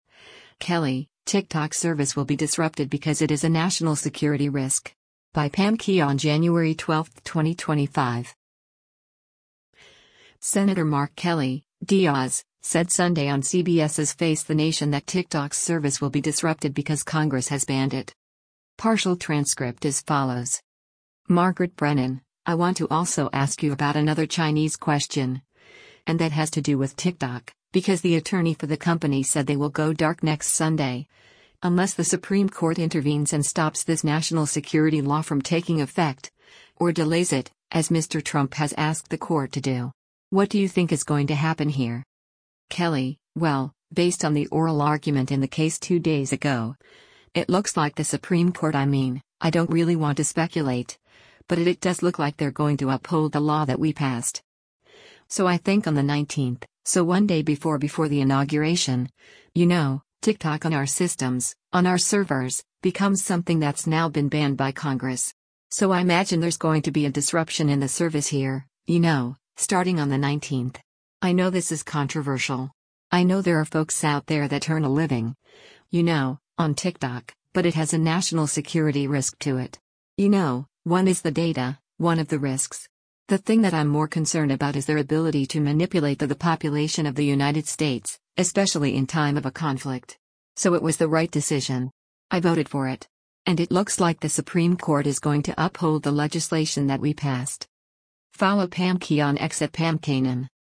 Senator Mark Kelly (D-AZ) said Sunday on CBS’s “Face the Nation” that Tiktok’s service will be disrupted because Congress has banned it.